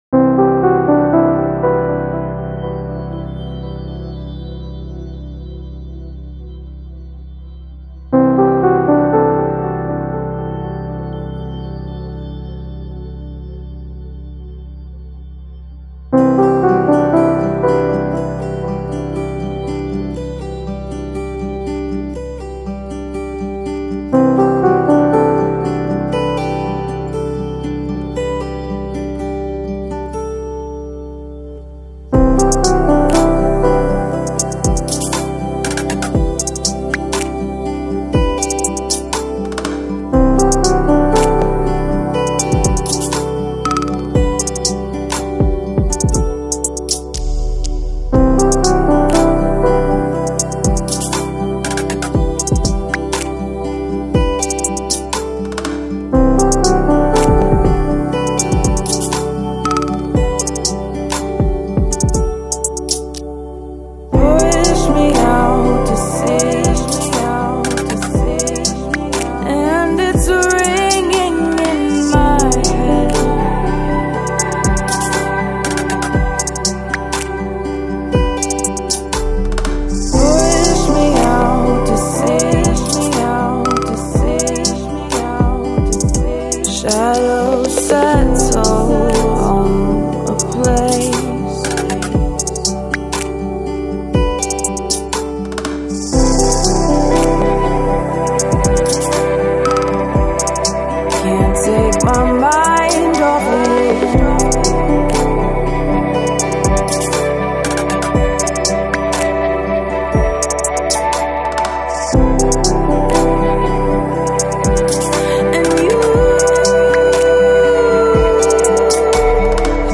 Жанр: Electronic, Lounge, Chill Out